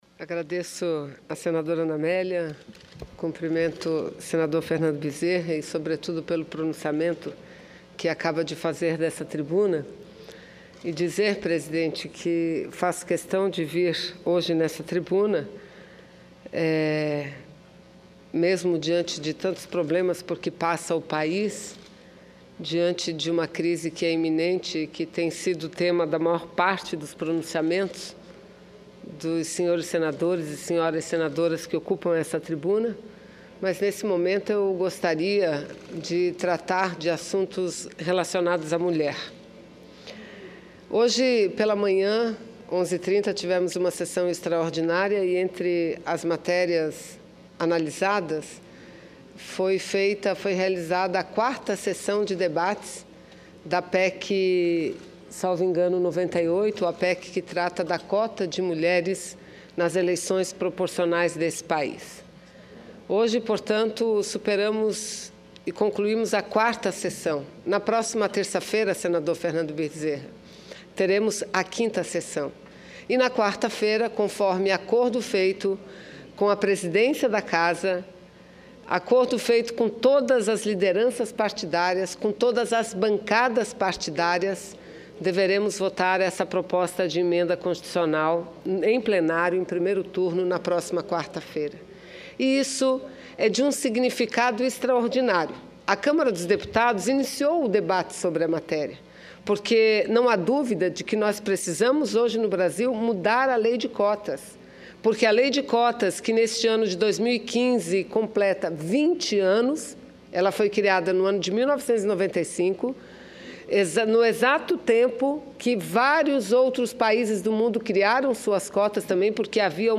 Plenário